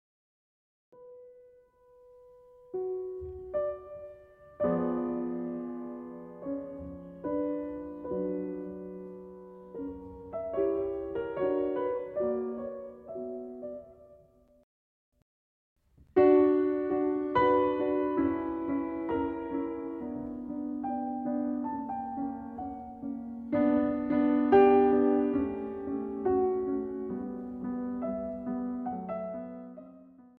short pieces for the piano